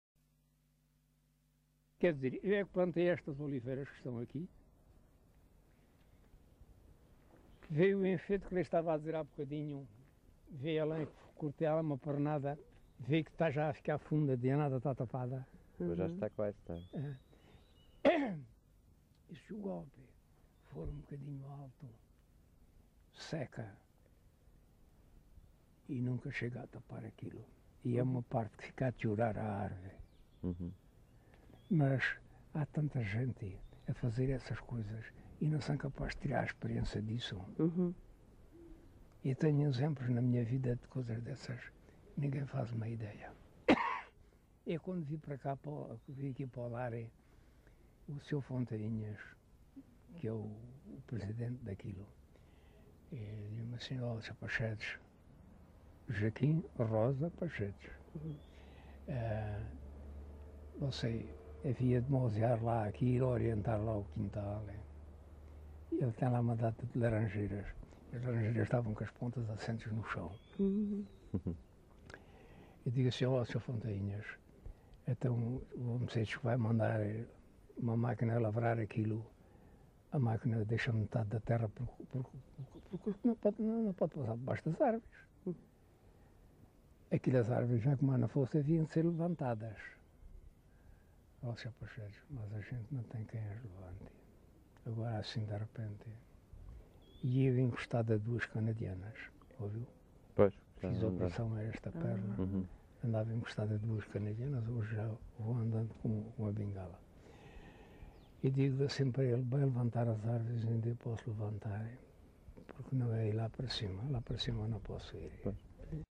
LocalidadeCabeço de Vide (Fronteira, Portalegre)